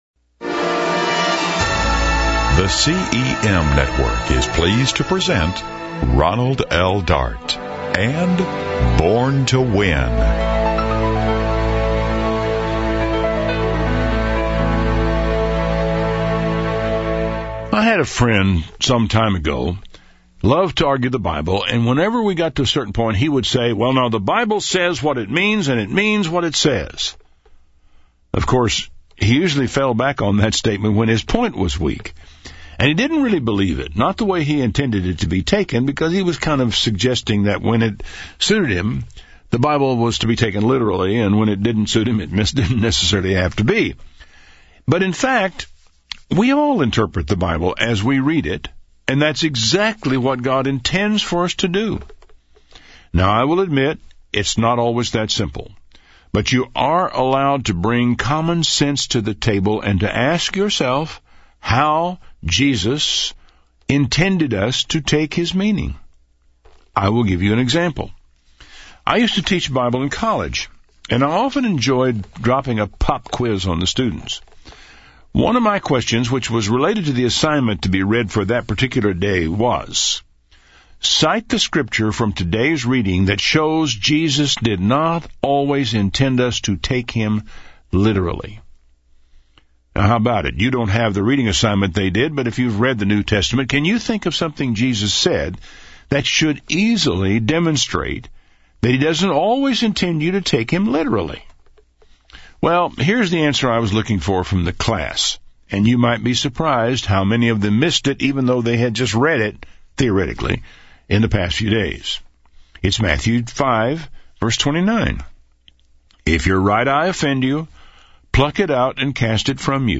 On the radio at AM 1710 and over the internet, KHMB Radio serves all the neighborhoods of Half Moon Bay with news, entertainment and information.